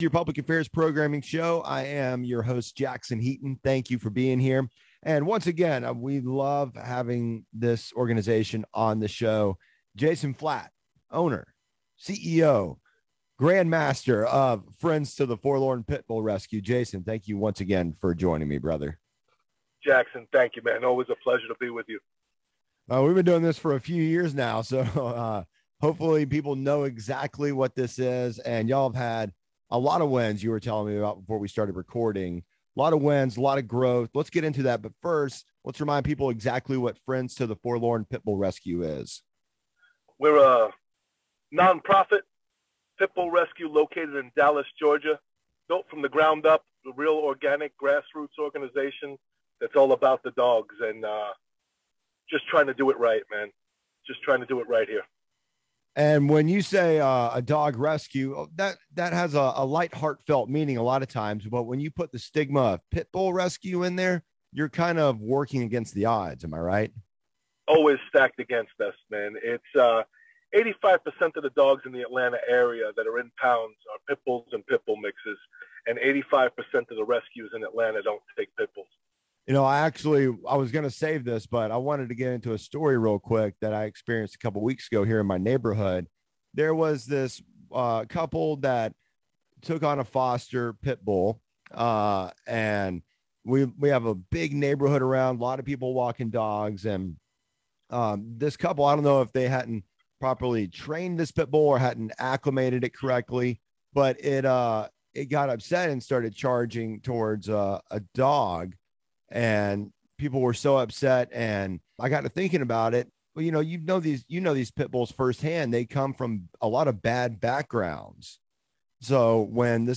Rock 100.5 Interview 10.8.21 | Friends to the Forlorn Pitbull Rescue